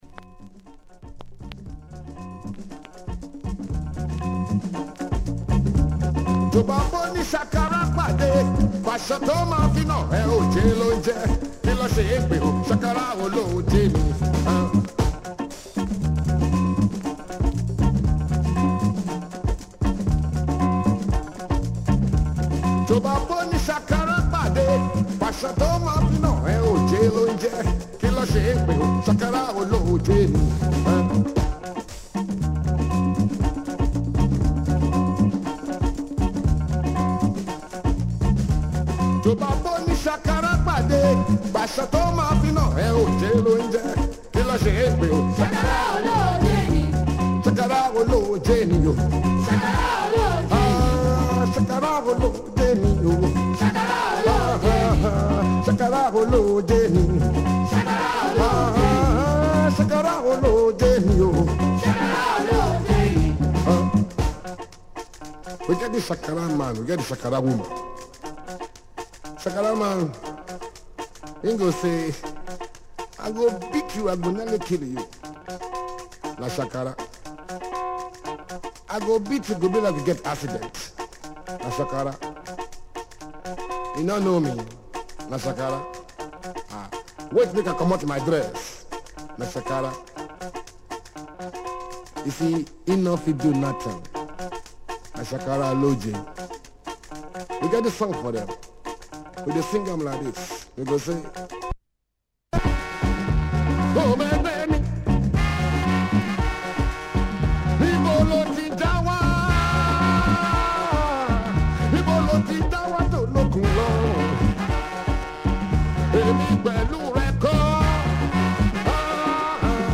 Top notch Lingala from this famous Congo group
Super horns on this one!